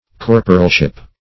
Corporalship \Cor"po*ral*ship\, n.